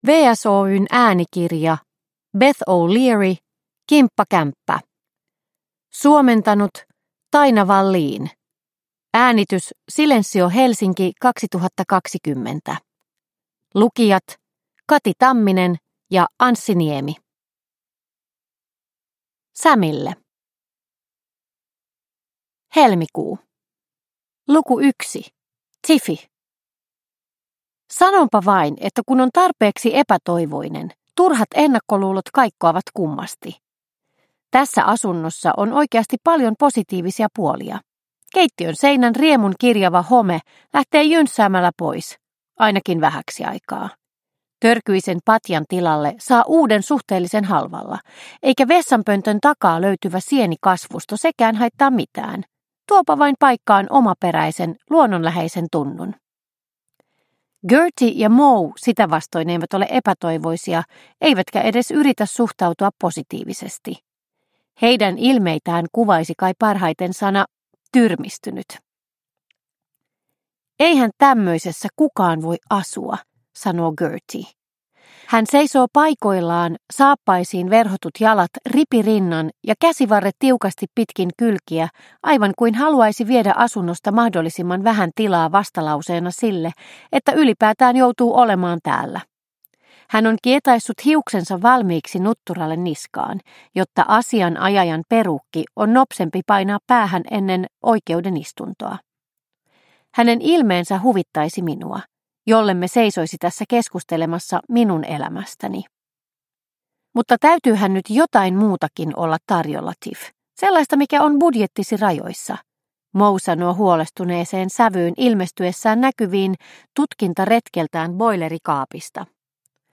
Kimppakämppä – Ljudbok – Laddas ner